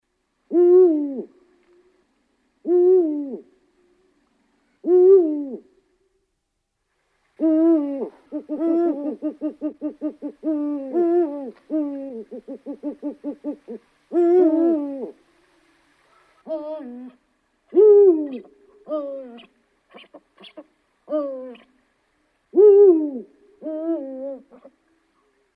Звуки филина
На этой странице собраны звуки филина — мощные крики, уханье и другие голосовые проявления этой величественной птицы.